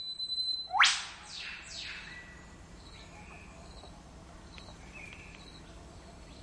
SDR 0011 鞭炮鸟1
描述：鞭鸟在森林里。东澳大利亚
Tag: 场记录 鞭鸟 自然 森林